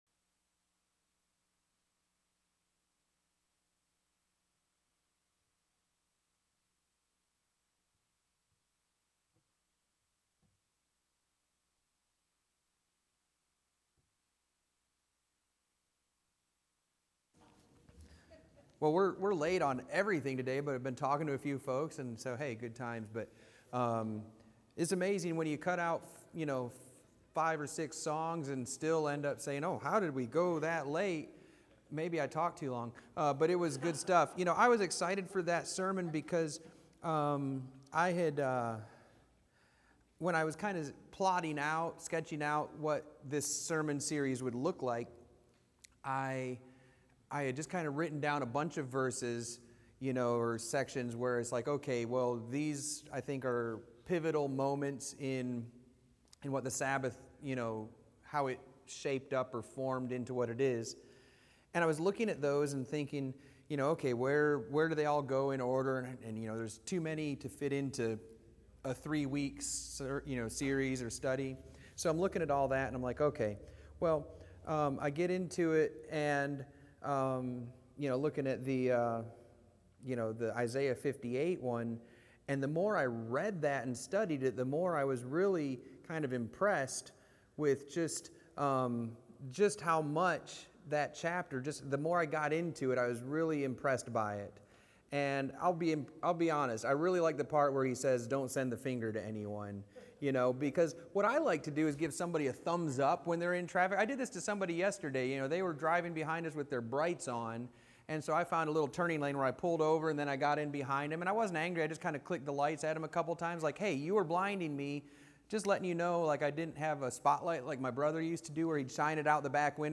S3 Ep36: SS Class audio for Sabbath 3